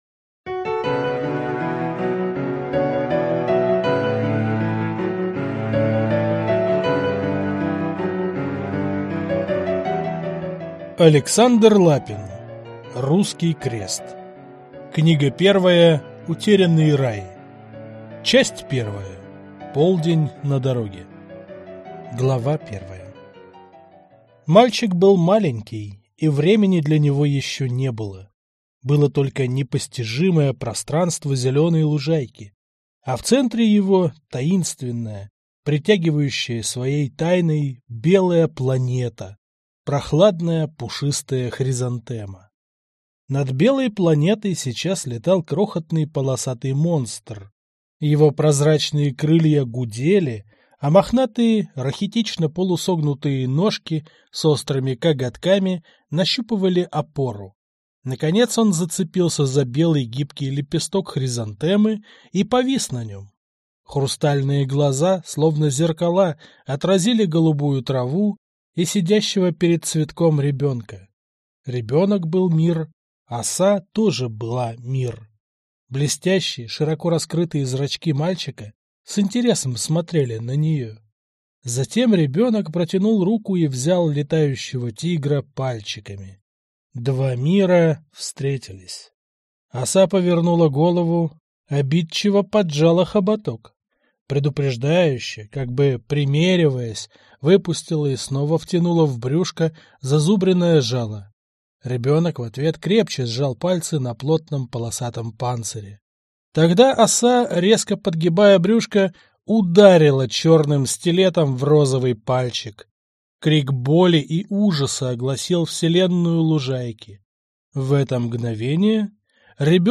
Аудиокнига Утерянный рай | Библиотека аудиокниг